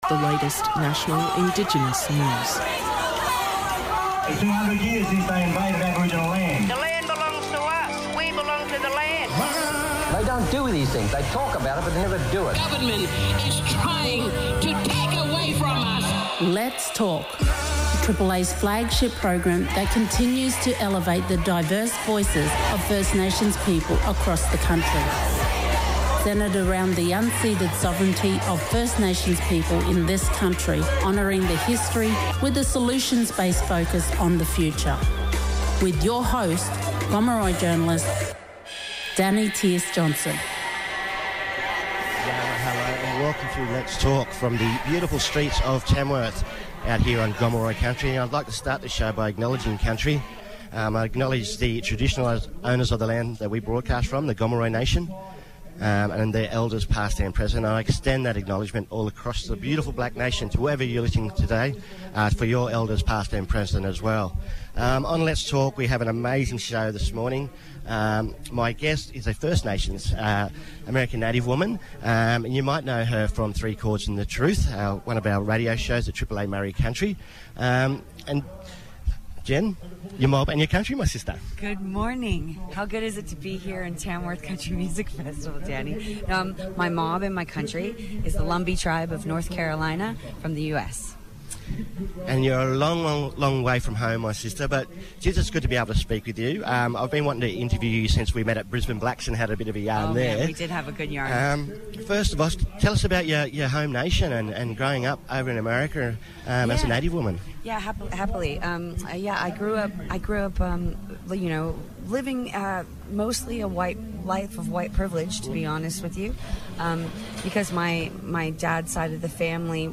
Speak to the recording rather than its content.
at the Tamworth country music festival!